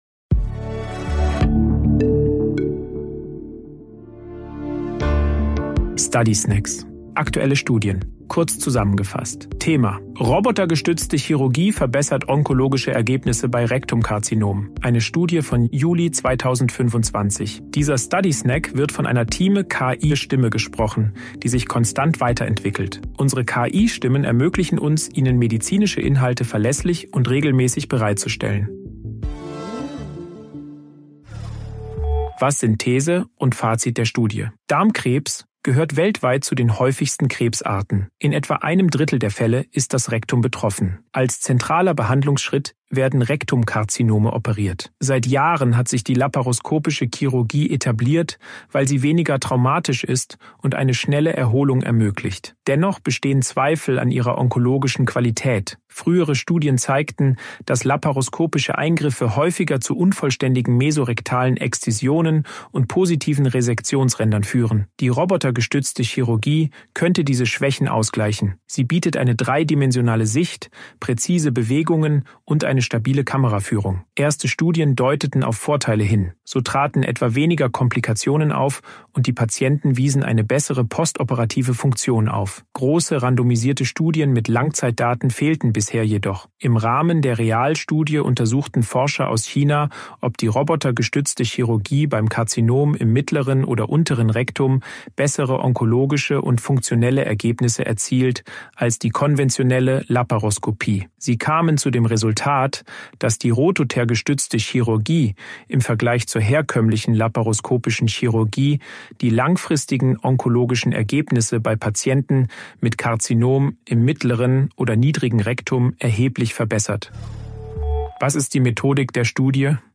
Hilfe von künstlicher Intelligenz (KI) oder maschineller